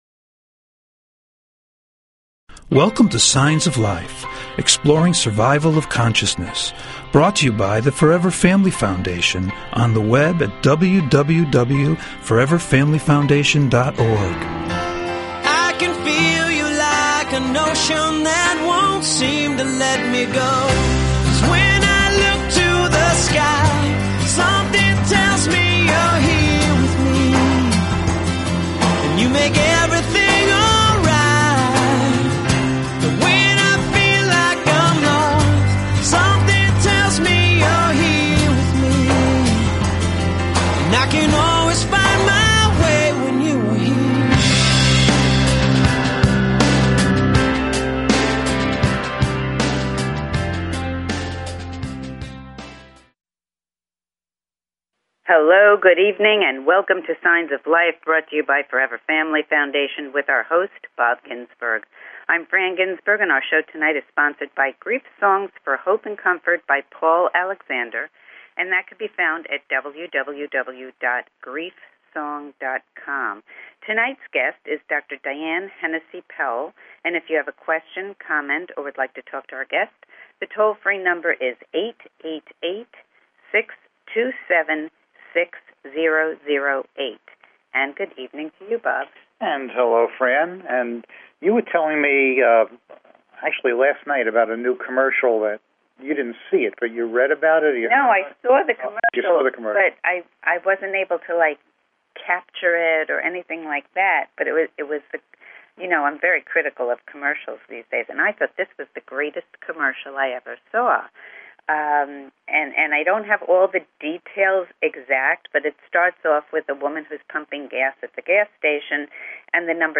Talk Show Episode, Audio Podcast, Signs_of_Life and Courtesy of BBS Radio on , show guests , about , categorized as
Call In or just listen to top Scientists, Mediums, and Researchers discuss their personal work in the field and answer your most perplexing questions.